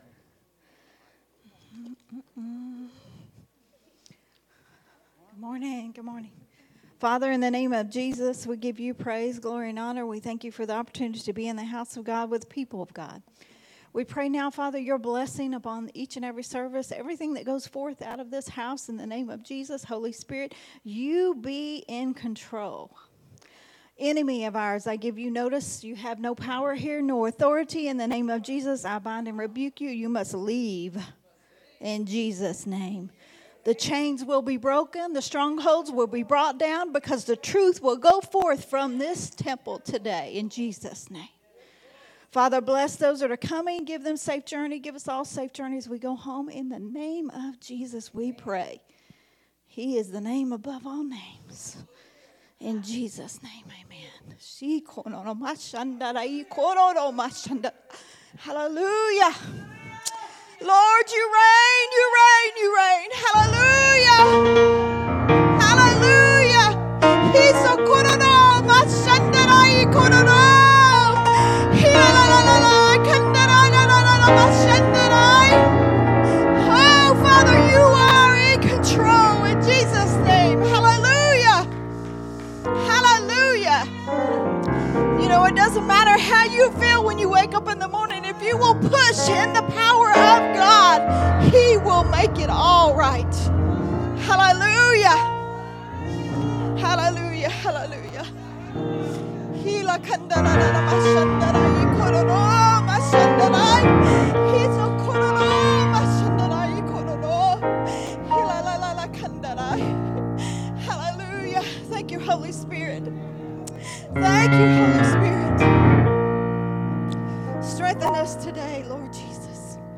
recorded at Unity Worship Center on January 7th, 2024.